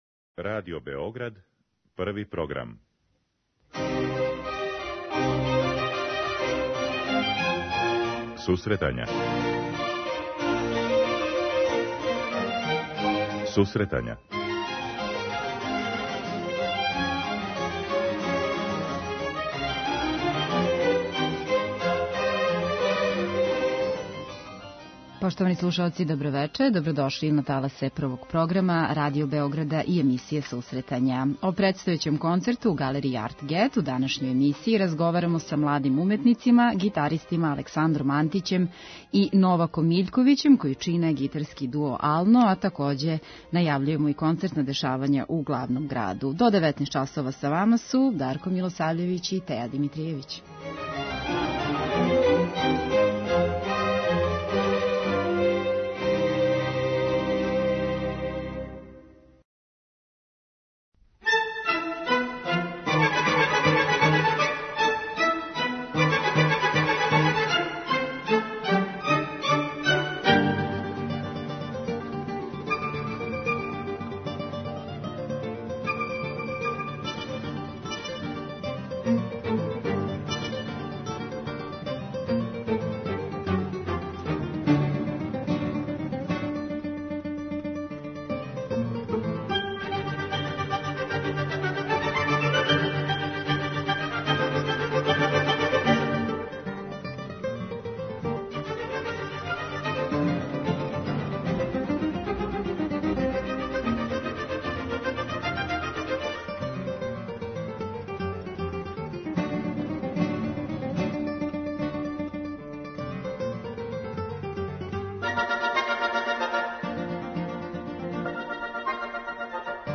у данашњој емисији разговарамо са младим уметницима, гитаристима